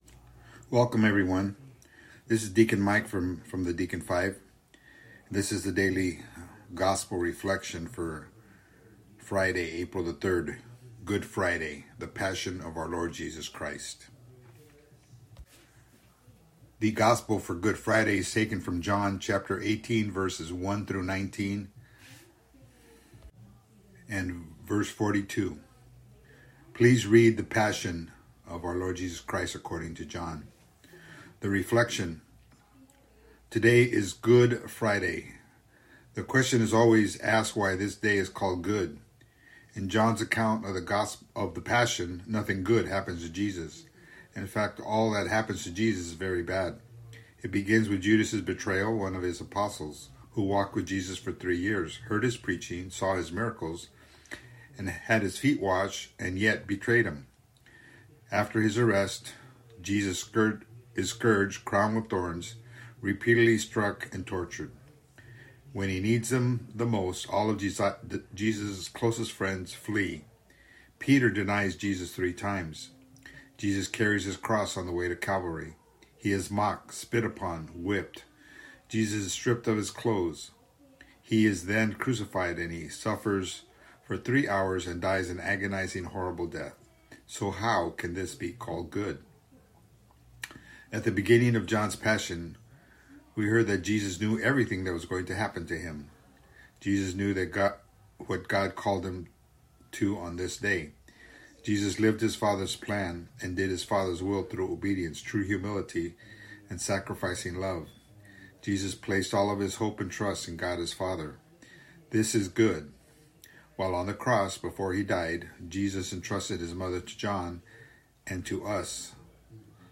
Audio Reflection: